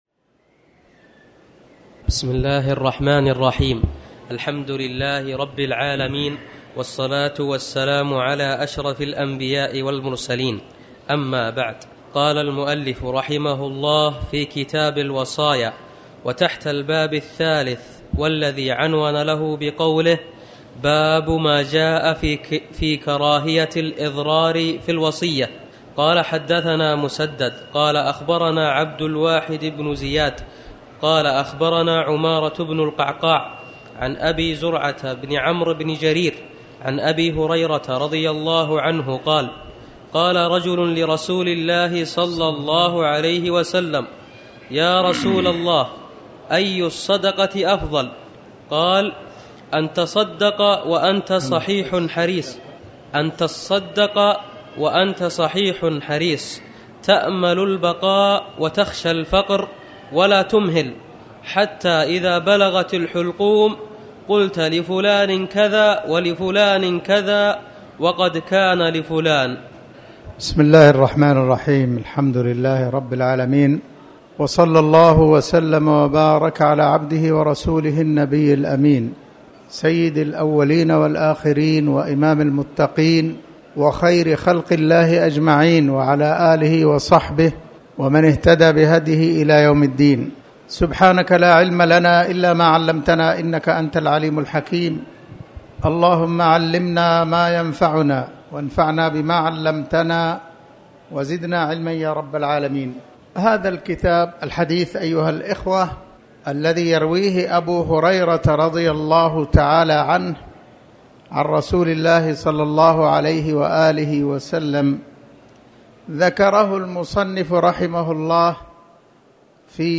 تاريخ النشر ٧ رجب ١٤٣٩ هـ المكان: المسجد الحرام الشيخ